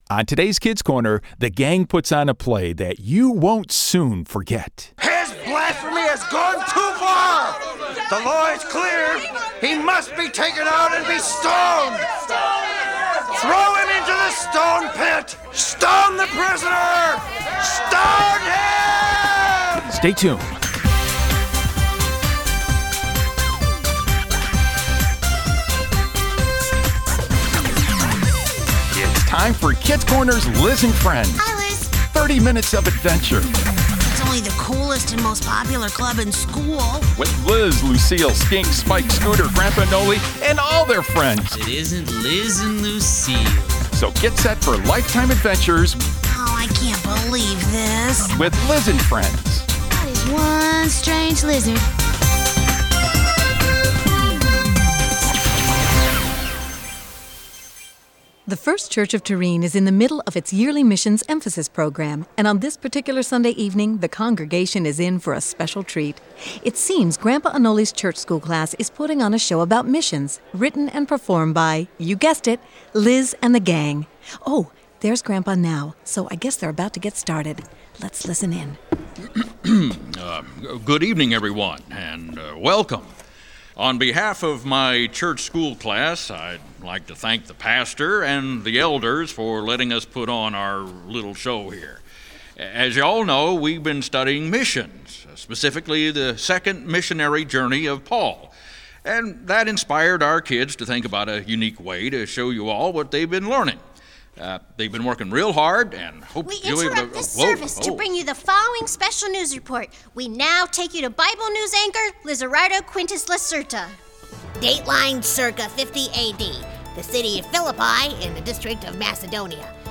It's time for Clubhouse Theater as Liz and his friends present a play about Paul, Silas, and the Philippian jailer.